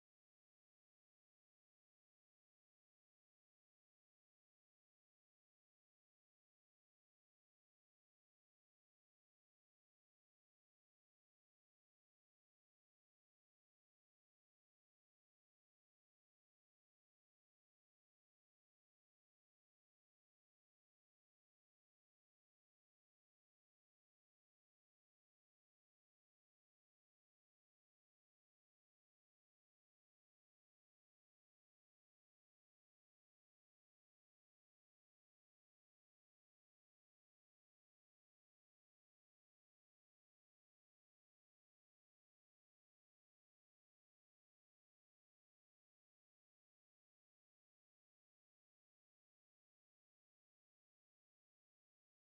挿入歌１の１